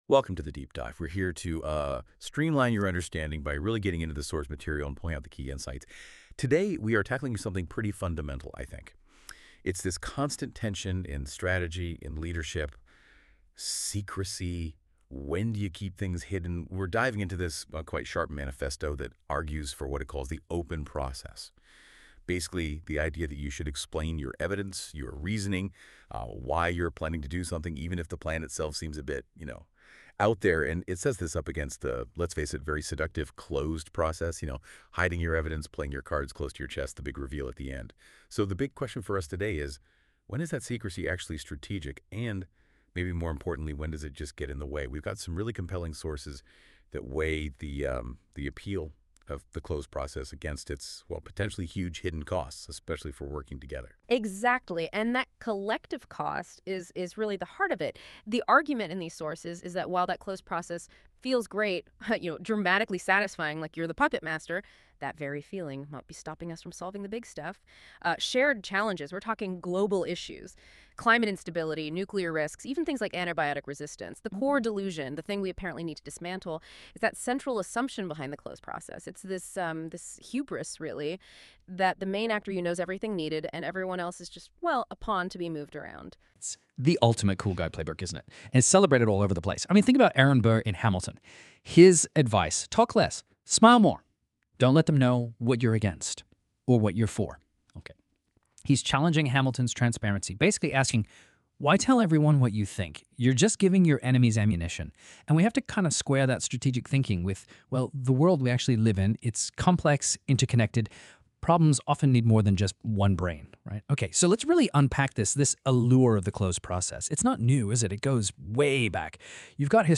This is another quite decent NotebookLM audio overview.